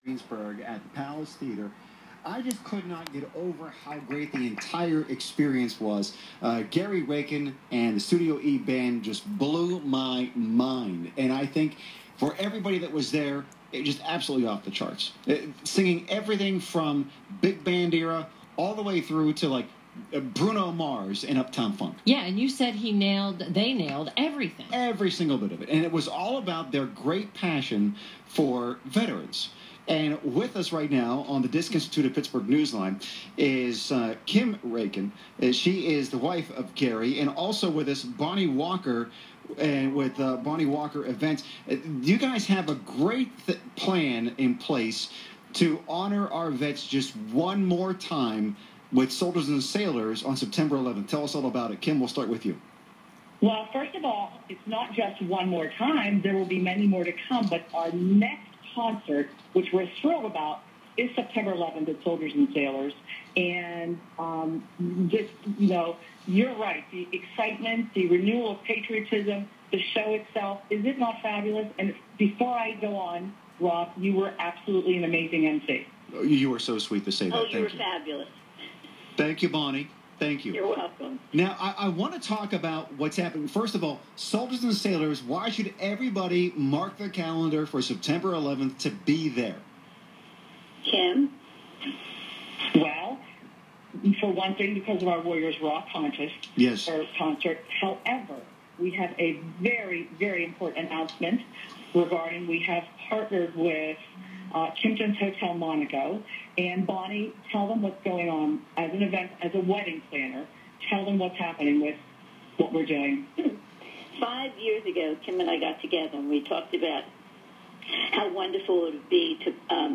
On Pittsburgh Radio for Warrior Weddings